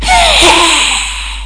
FScream2.mp3